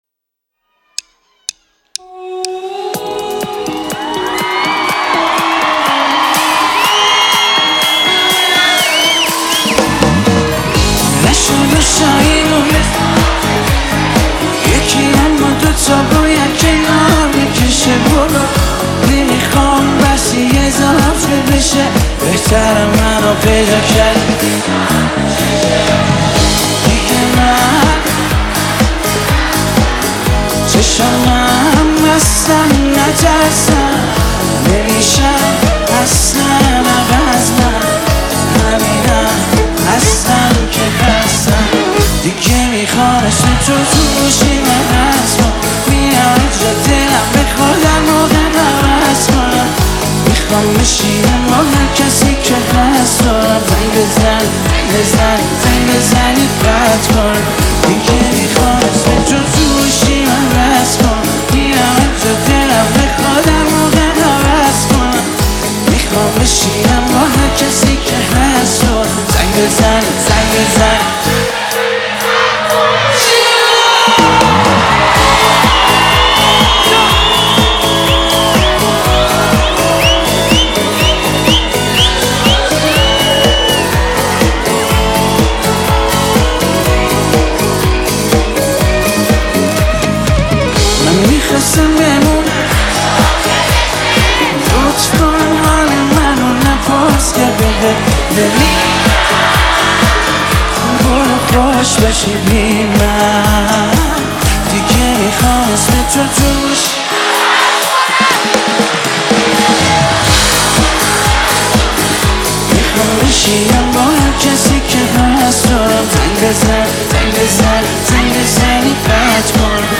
(live In Concert)